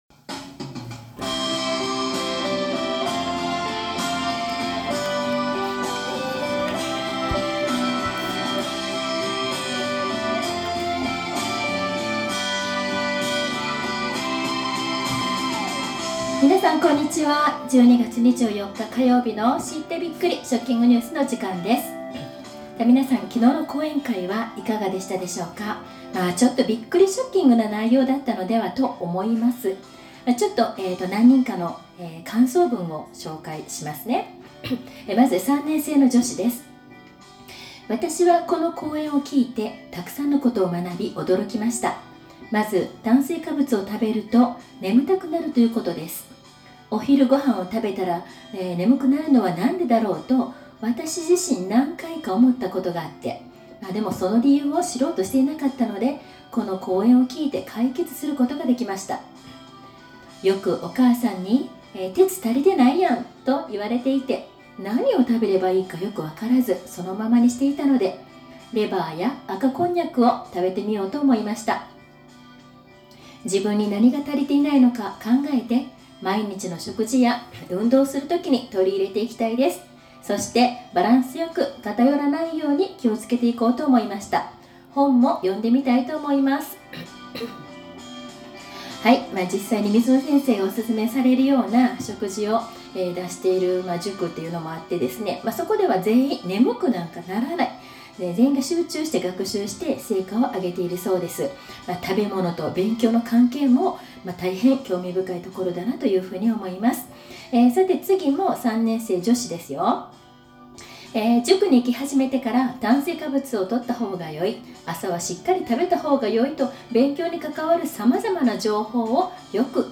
１２月２４日の食育放送